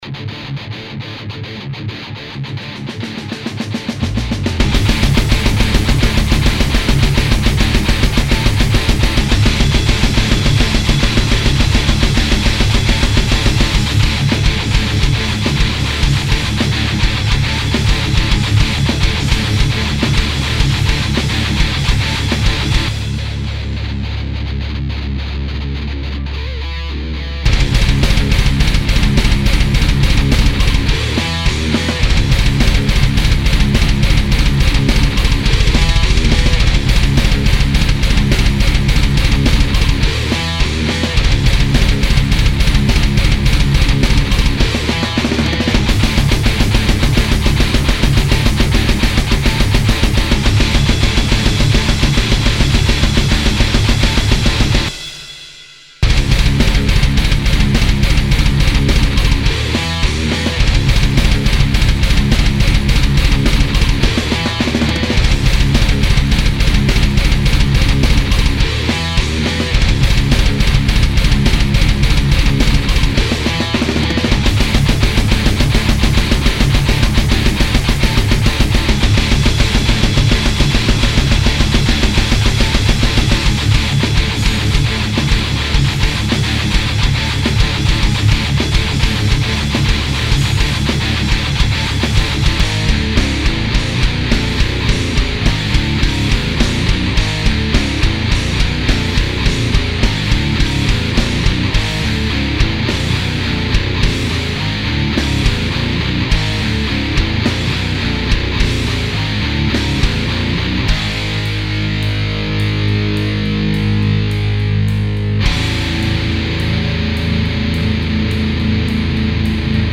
Vos Compos Metal
(la 2e version, beaucoup plus proche de mon mix original)
Je préfère le mix sans izotope, il est plus 'clair'.